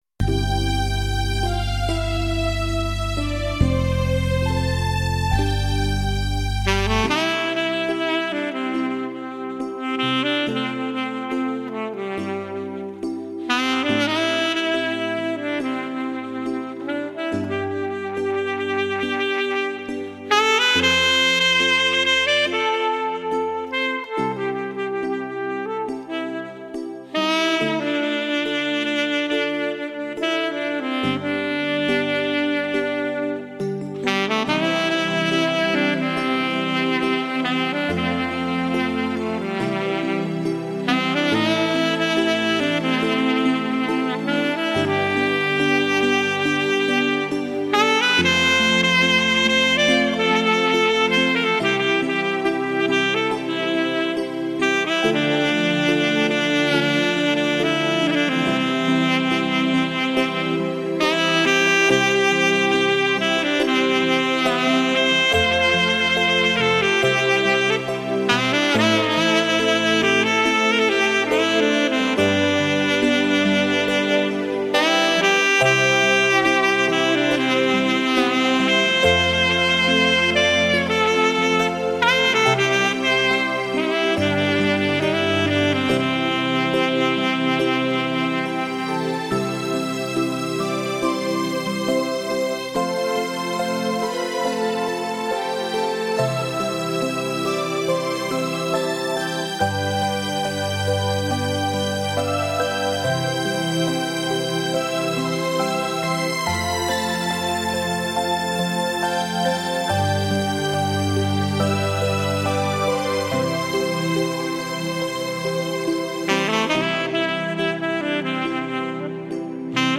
萨克斯独奏